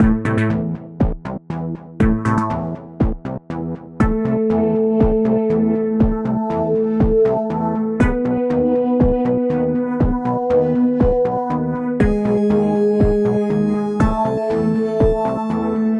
サイバー空間の洞窟にいるようなシーンにぴったりのBGMです！
ループ：◎
BPM：120
キー：Em
ジャンル：みらい
楽器：シンセサイザー